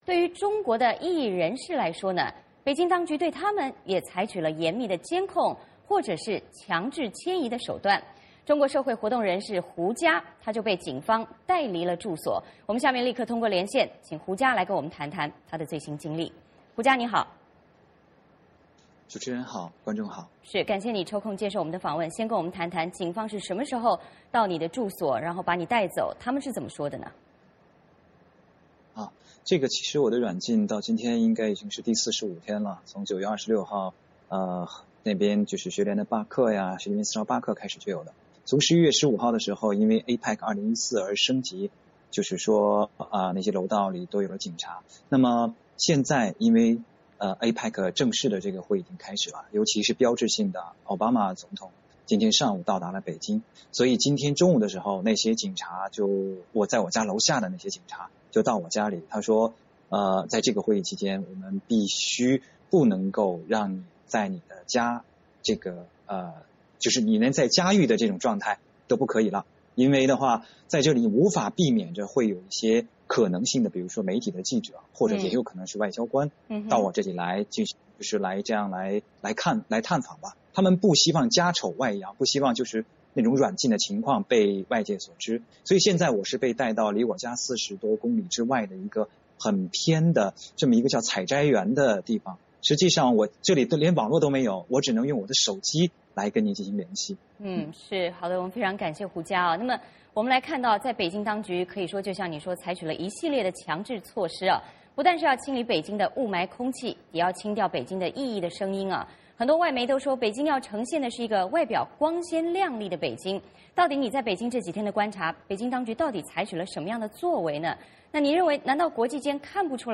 VOA连线：胡佳：APEC峰会是谈论人权的最佳机会
除了阻挡香港占中学生进入北京，对中国异议人士，北京当局也采取了严密监控或是强制迁移的手段，中国社会活动人士胡佳就被警察带离住所。我们通过连线邀请了胡佳来谈谈他的最新经历。